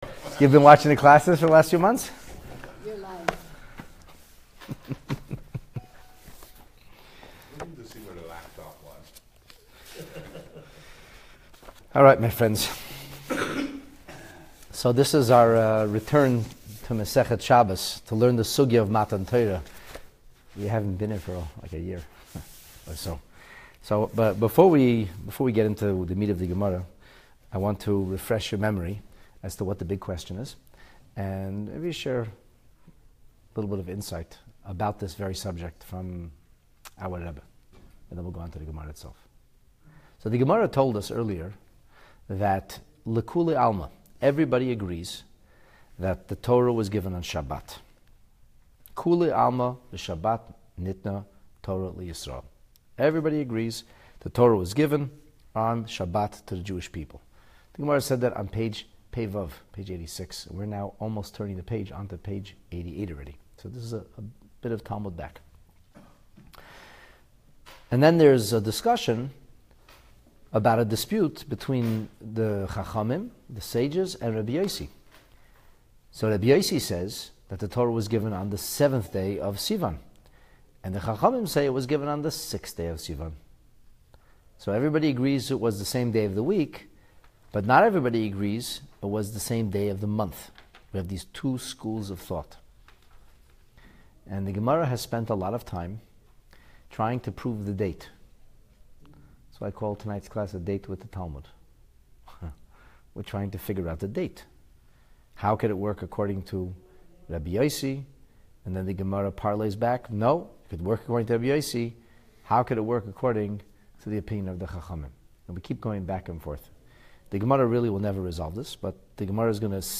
Dating Our Anniversary: The Talmud on the giving of the Torah (Part 5) The Talmud continues its quest for the exact date of Matan Torah by carefully analysing scriptural framing of historical events. This class provides profound insight into the workings of the Jewish calendar, which ultimately render the drawing of a precise scheduling conclusion impossible.